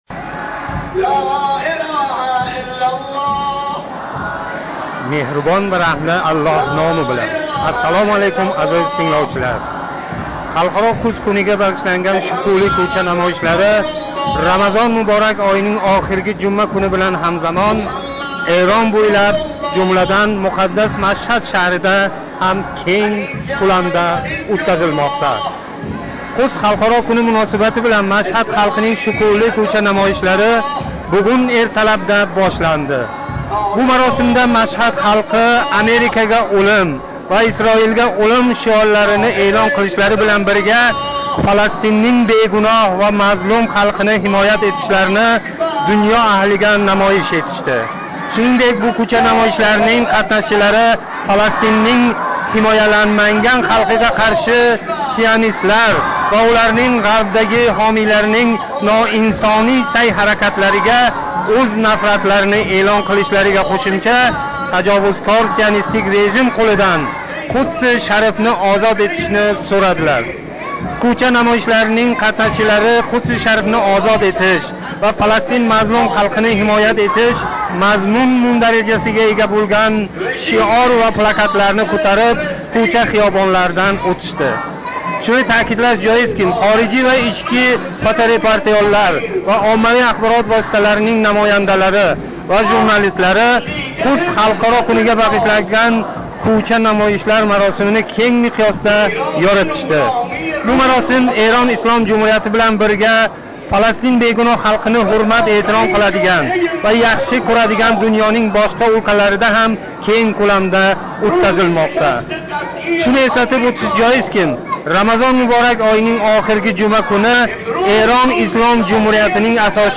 Халқаро Қудс кунига бағишланган Машҳаддаги кўча намоишлари